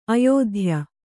♪ ayōdhya